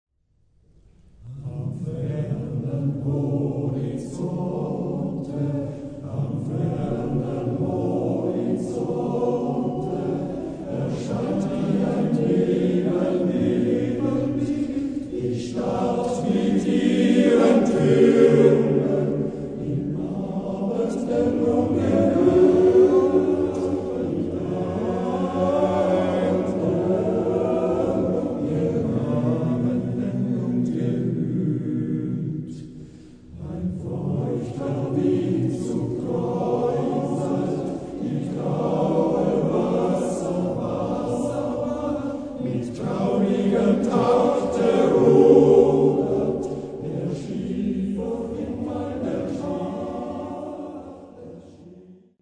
Der Liederkranz am Ottenberg ist ein regionaler Männerchor mit Sitz in Weinfelden, der den gehobenen Gesang pflegt.
Gesangfest Bussnang 4.9.2010